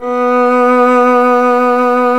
Index of /90_sSampleCDs/Roland - String Master Series/STR_Violin 1 vb/STR_Vln1 % marc